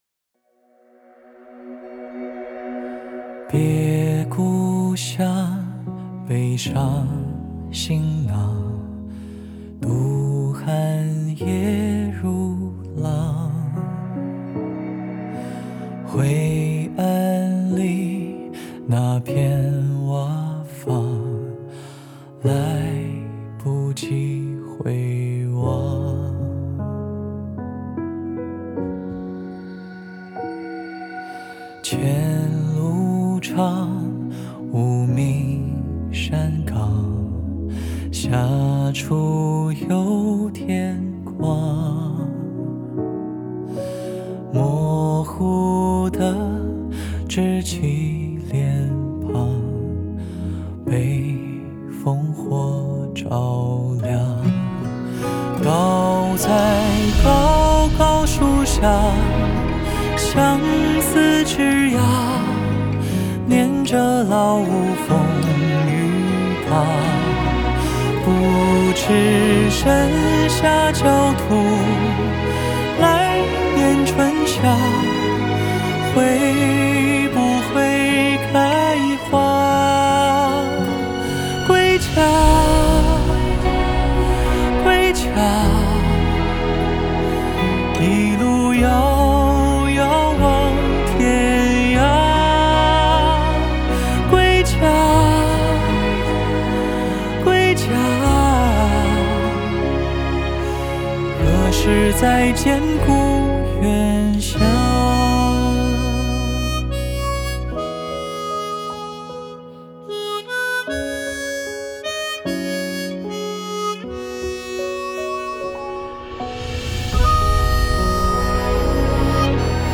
Ps：在线试听为压缩音质节选，体验无损音质请下载完整版
吉他
口琴
弦乐